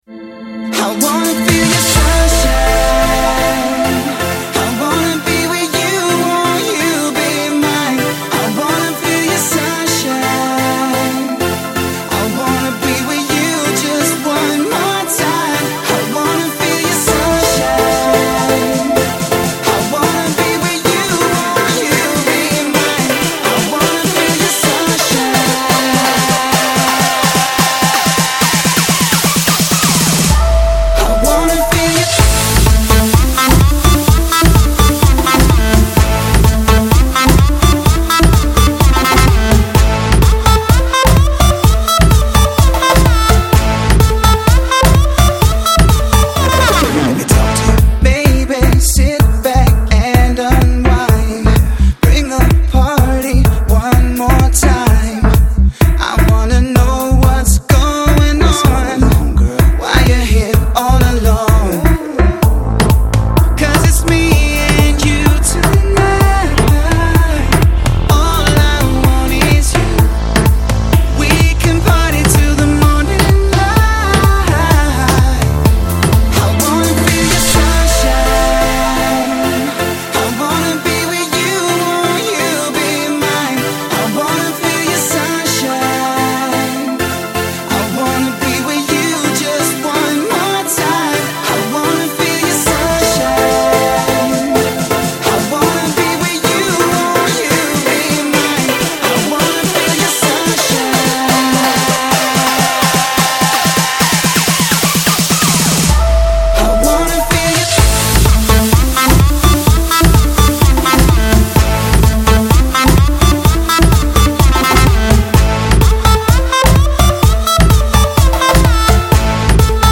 ένα νέο εθιστικό καλοκαιρινό track